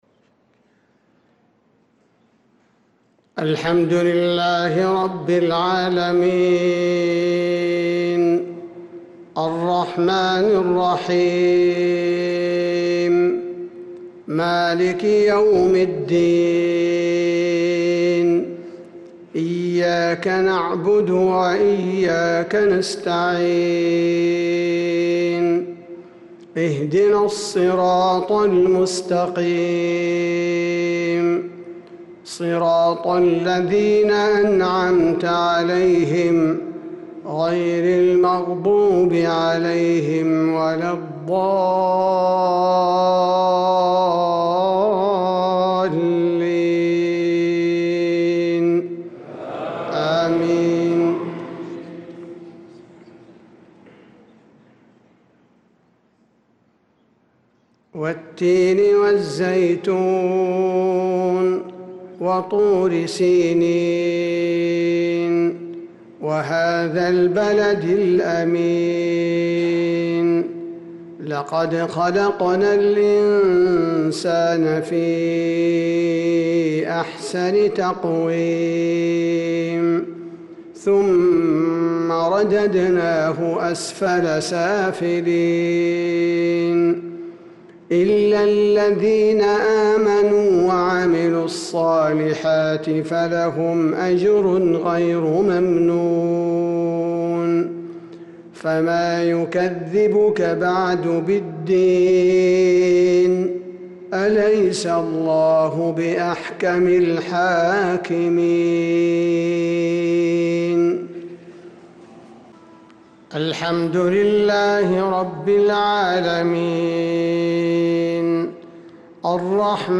صلاة المغرب للقارئ عبدالباري الثبيتي 25 ربيع الآخر 1446 هـ
تِلَاوَات الْحَرَمَيْن .